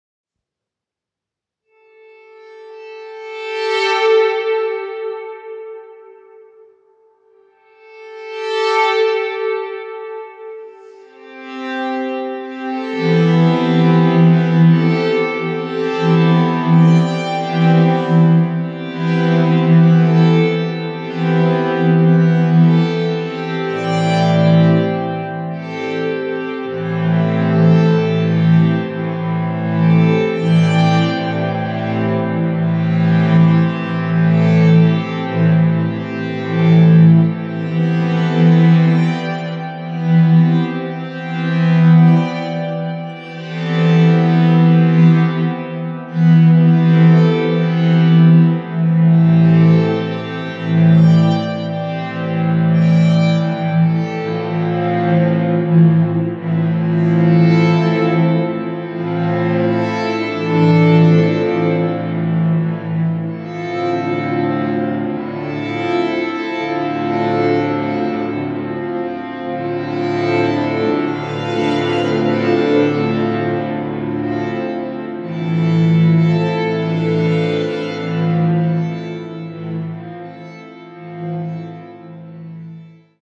Musiques américaines du 20e siècle
violons
violoncelle